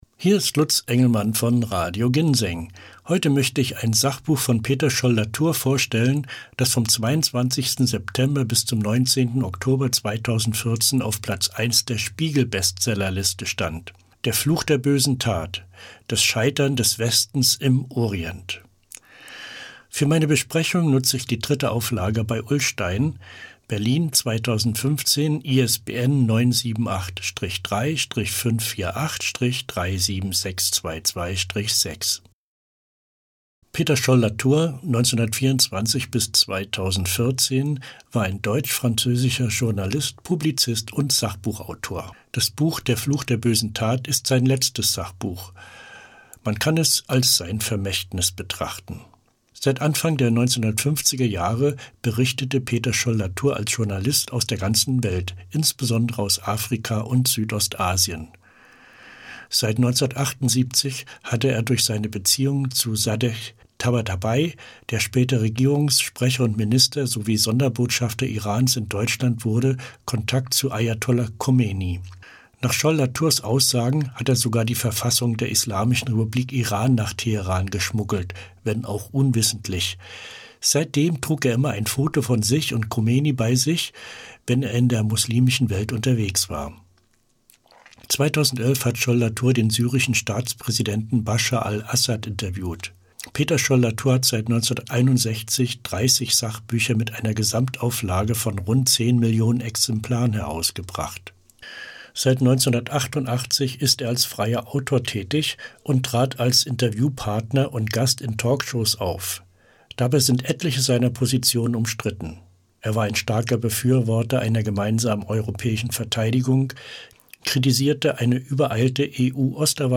Sachbuchempfehlung